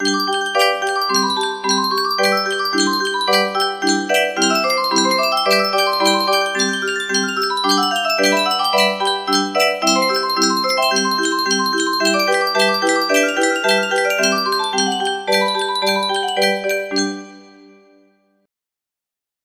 Grand Illusions 30 (F scale)